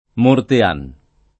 [ morte # n ]